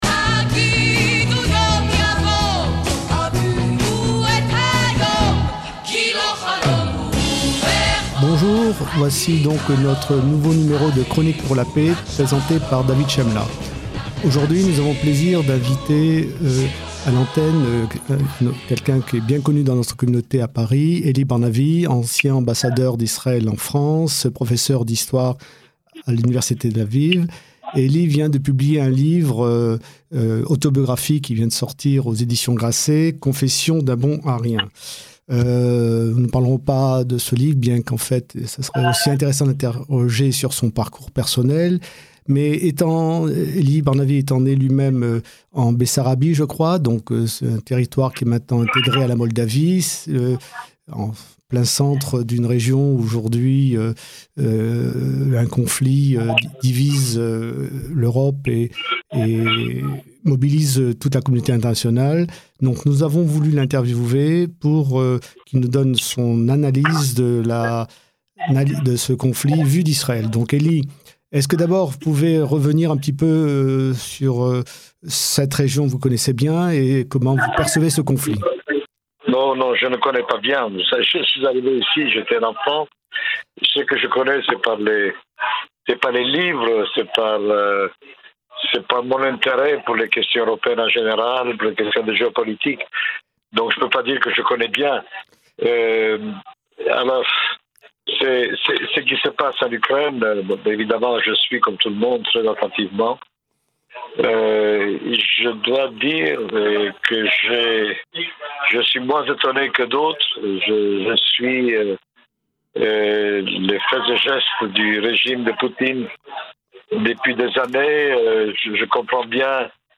émission bimensuelle sur Radio Shalom